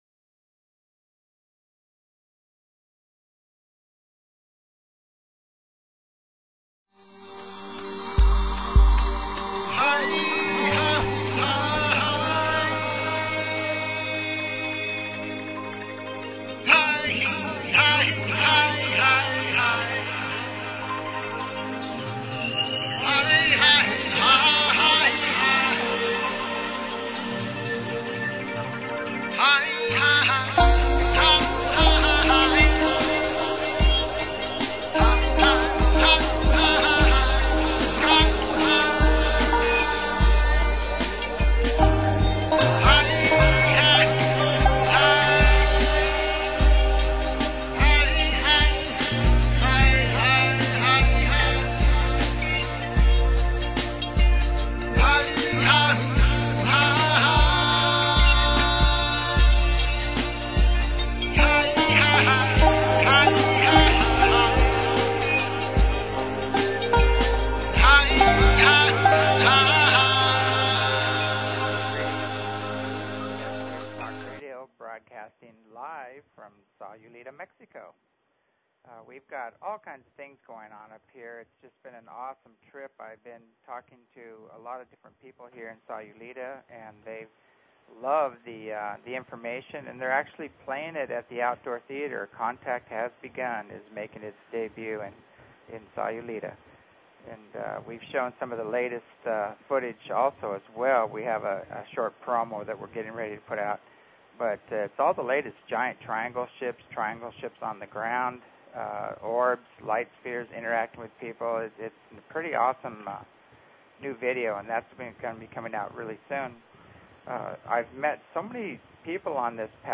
Talk Show Episode, Audio Podcast, As_You_Wish_Talk_Radio and Courtesy of BBS Radio on , show guests , about , categorized as
Broadcast Live from Sayulita Mexico the Houichol Shamas